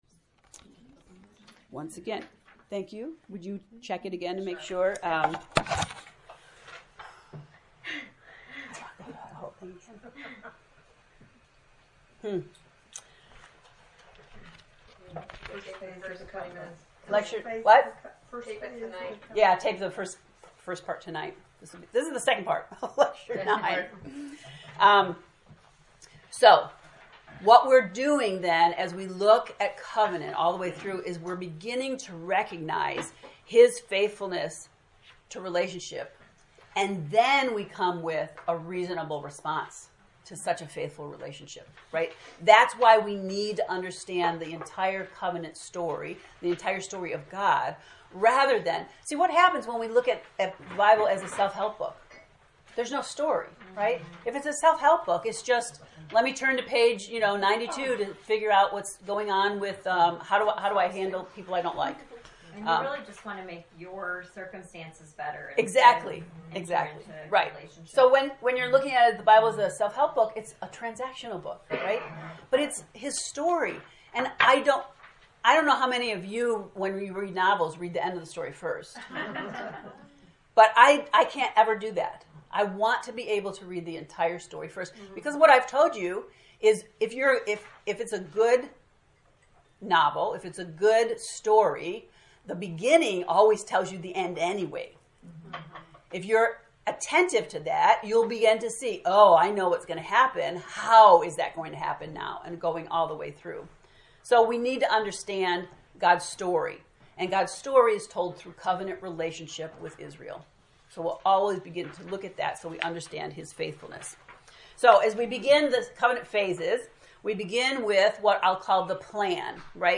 COVENANT lecture 9